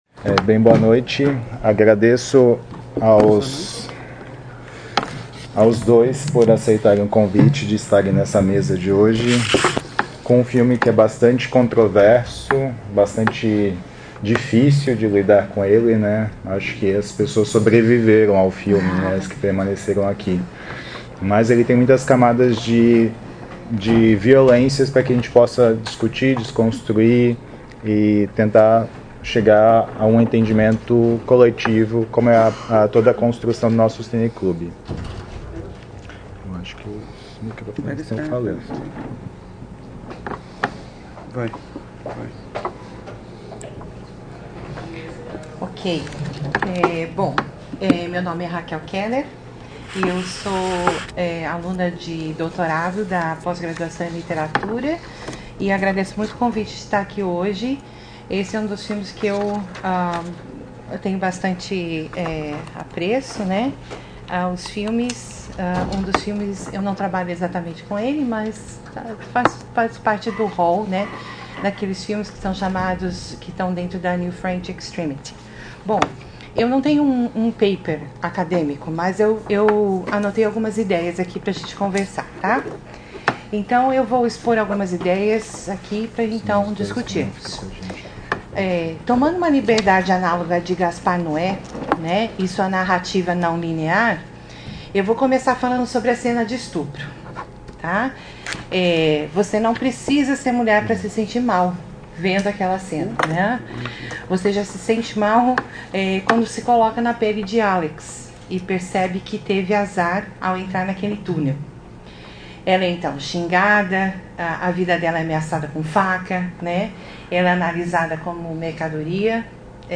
Comentários dos debatedore(a)s convidado(a)s
na sessão de exibição e debate do filme "Irreversível"
realizada em 15 de agosto de 2019 no Auditório Elke Hering da Biblioteca Central da UFSC.